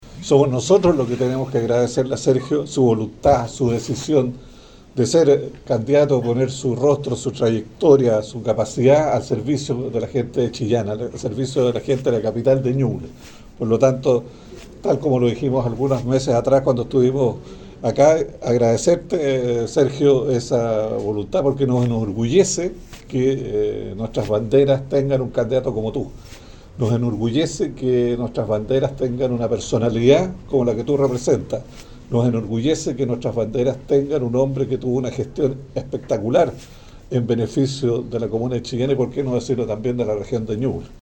Víctor Pérez, exsenador por la región y exministro del Interior, destacó la trayectoria de Zarzar como alcalde y subrayó la importancia de su liderazgo para retomar proyectos clave para la comuna de Chillán.